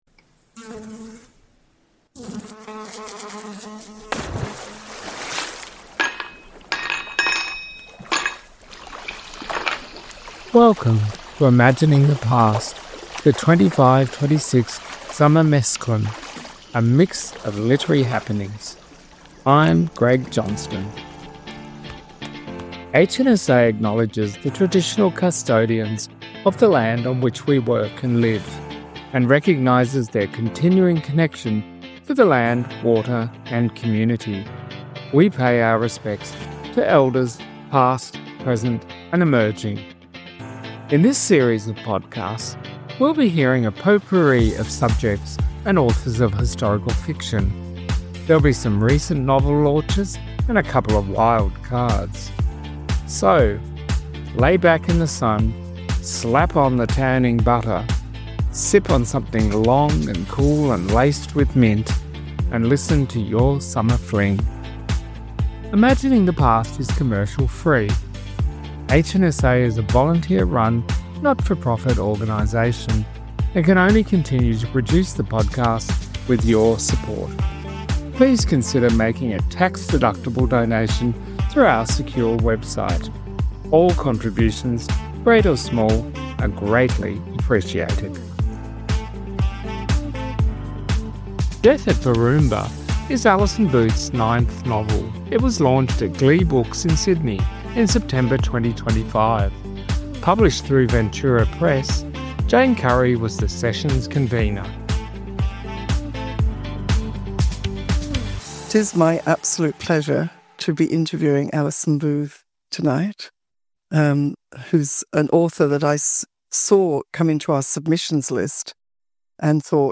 Enjoy catching up with book launch discussions featuring your favourite authors and new voices.